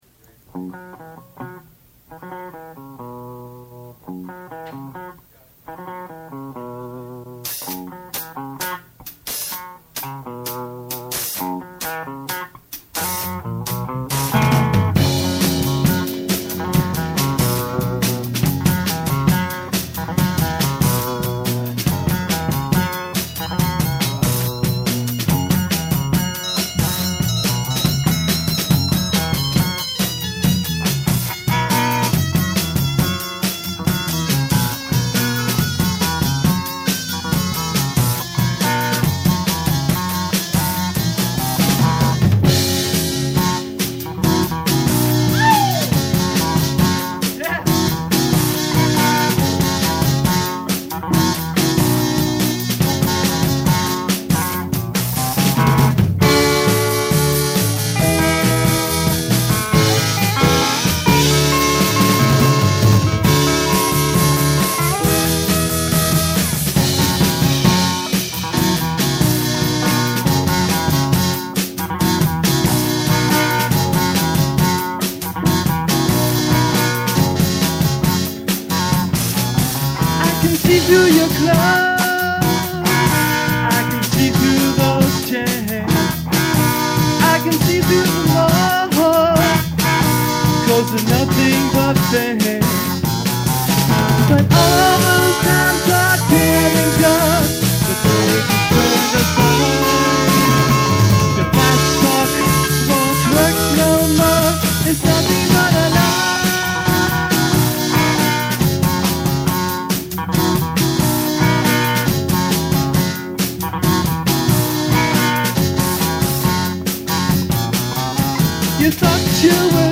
percussion, vocals
bass guitar
keyboards, vocals
guitar, vocals